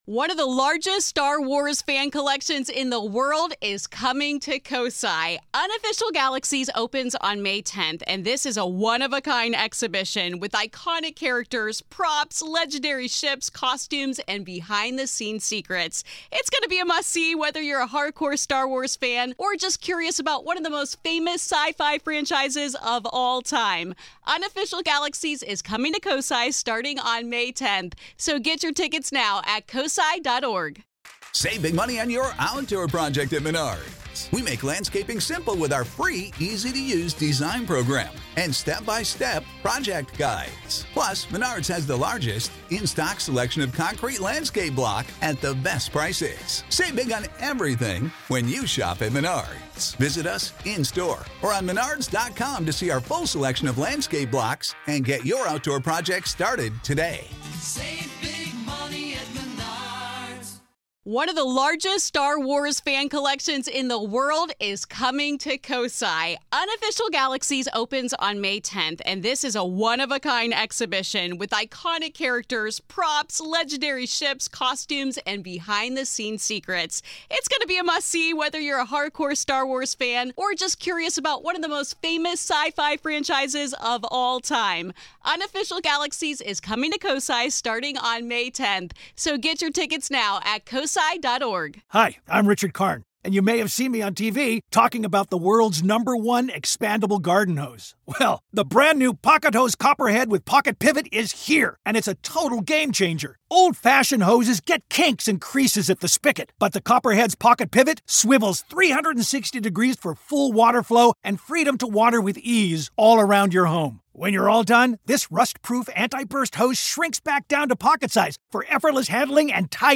But as rumors of sobbing apparitions and flickering lanterns in the crypt suggest, they may have some spirited guests to accommodate—guests that checked in over a century ago and never checked out. This is Part Two of our conversation.